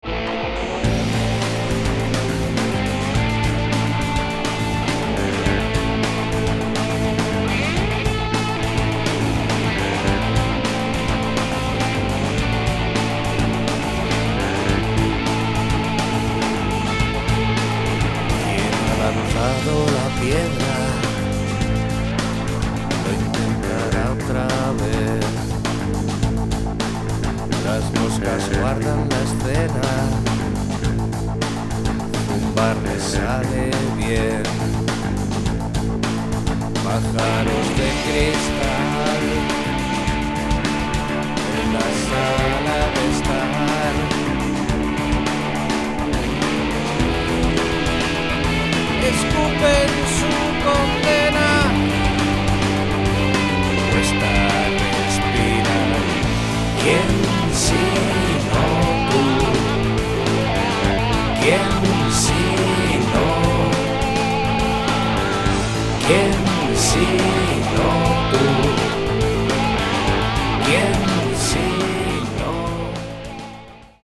Pop/Rock